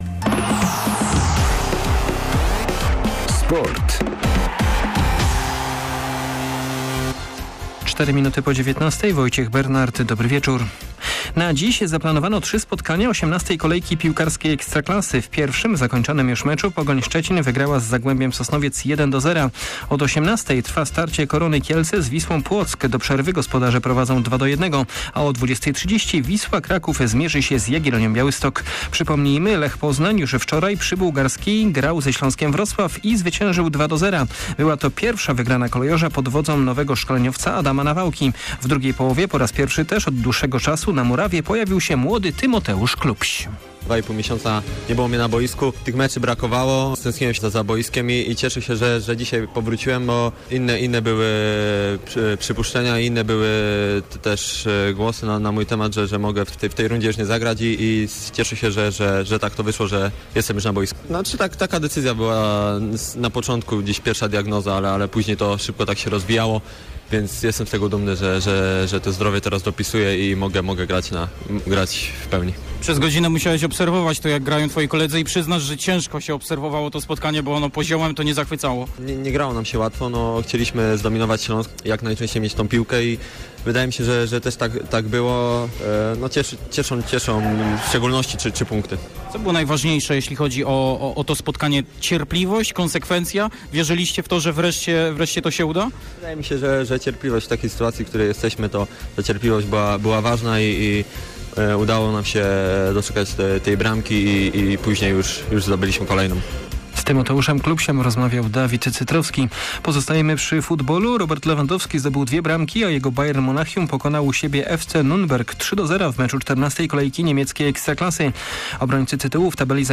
08.12. SERWIS SPORTOWY GODZ. 19:05